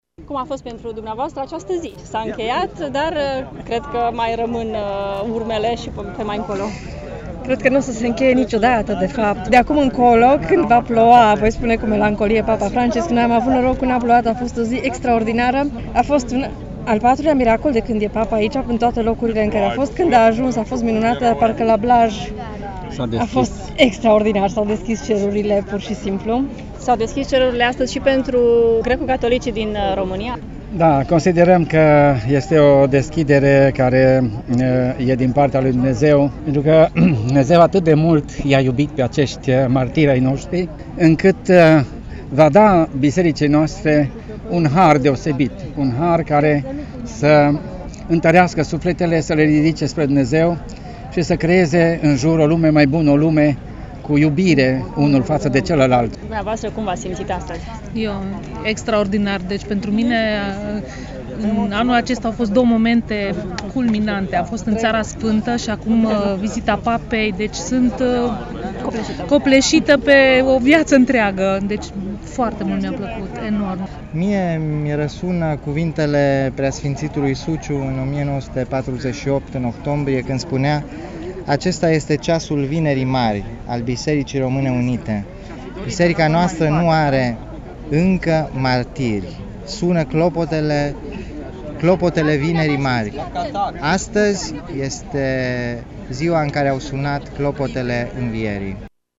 Câțiva dintre cei prezenți i-au spus reporterului Radio Tg.Mureş, cu ce gânduri au rămas după întâlnirea cu Sfântul Părinte: